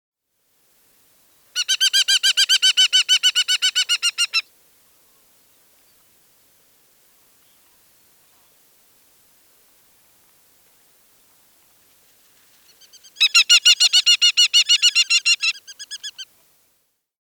Звуки сокола
Южномексиканский сокол общается с партнером похожим на него